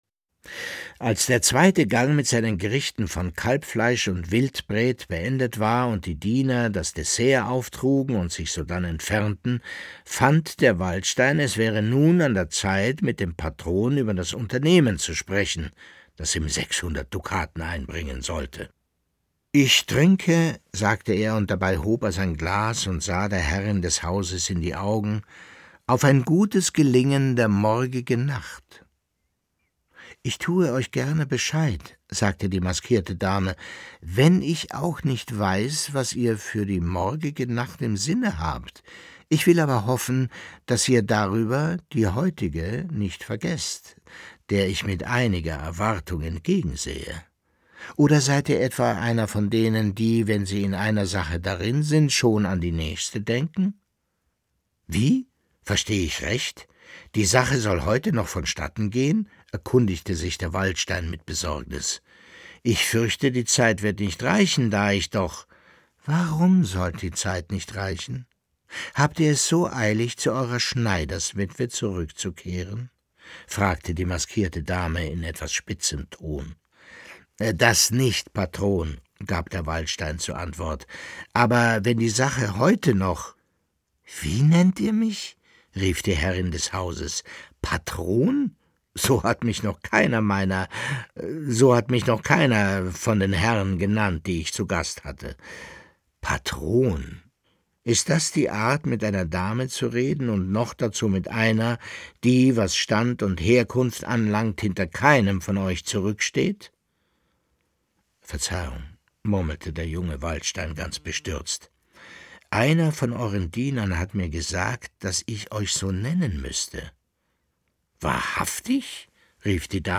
Leo Perutz: Nachts unter der steinernen Brücke (13/25) ~ Lesungen Podcast